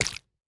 Minecraft Version Minecraft Version latest Latest Release | Latest Snapshot latest / assets / minecraft / sounds / block / honeyblock / break3.ogg Compare With Compare With Latest Release | Latest Snapshot